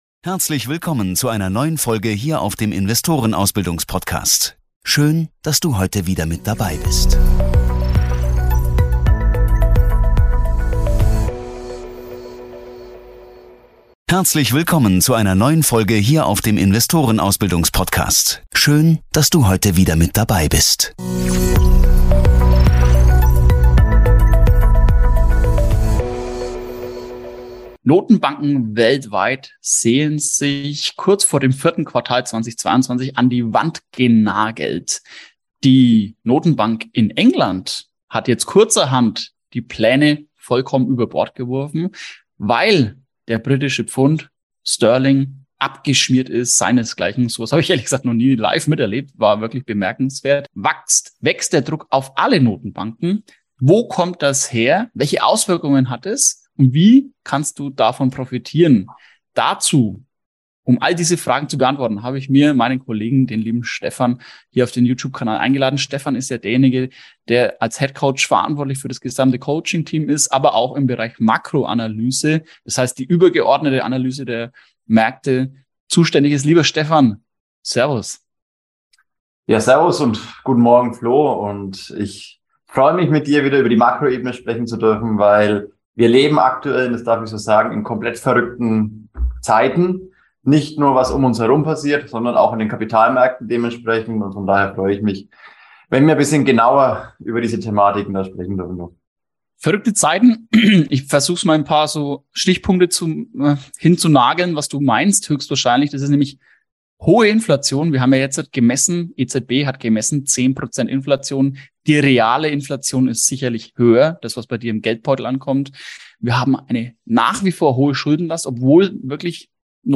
Dieses Interview besteht aus 2 teilen, der zweite Teil kommt nächsten Dienstag, den 08.11.2022.